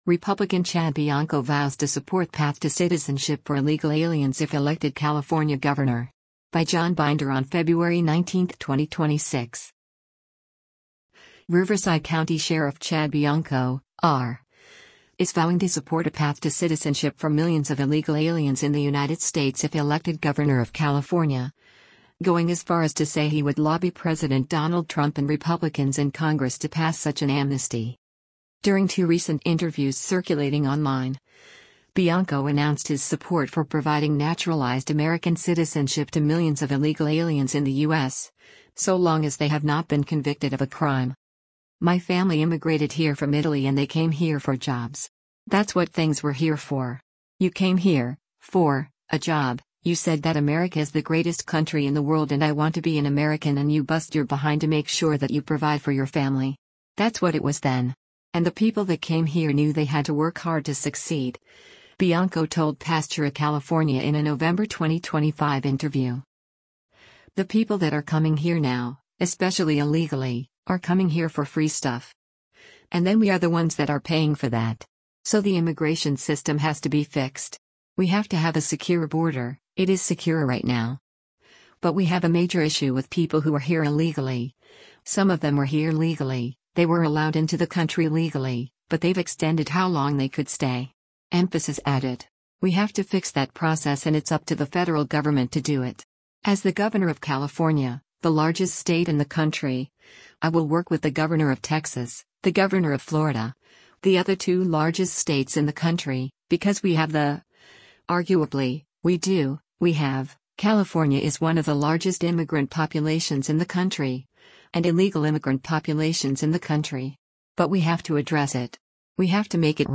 During two recent interviews circulating online, Bianco announced his support for providing naturalized American citizenship to millions of illegal aliens in the U.S., so long as they have not been convicted of a crime.